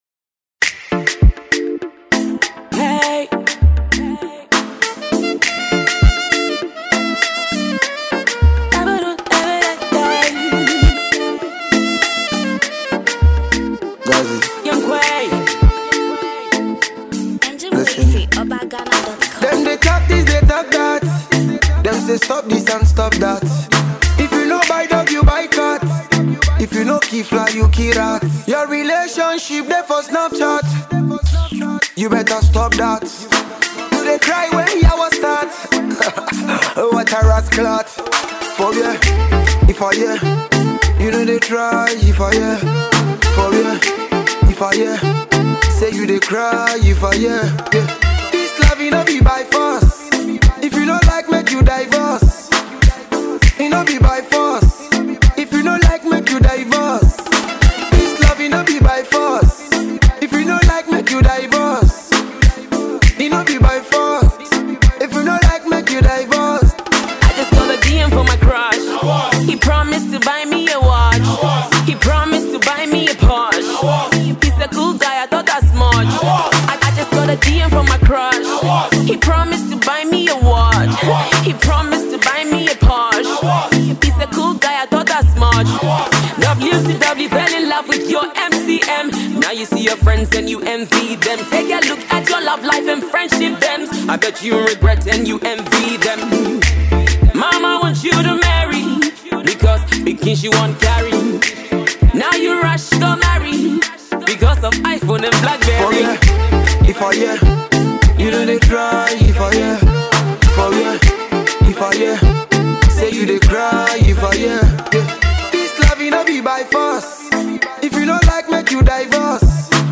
Ghana Music
Ghanaian talented songstress